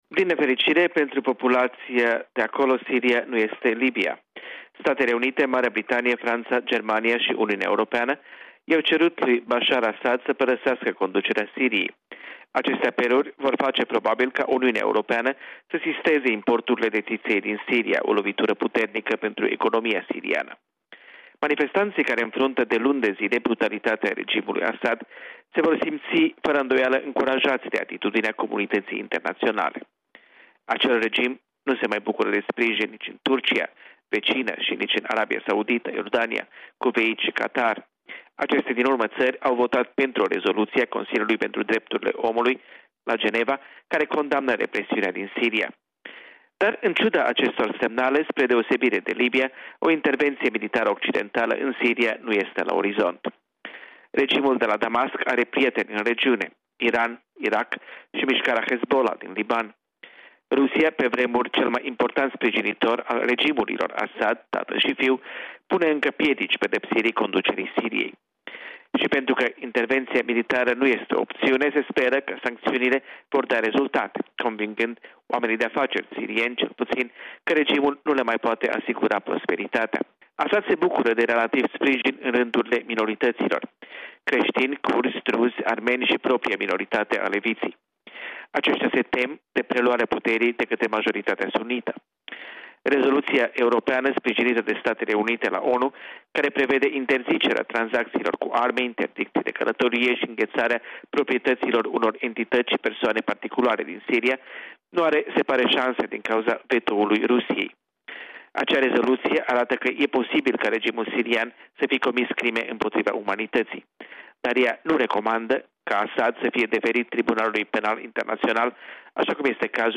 Corespondenţa zilei de la Washington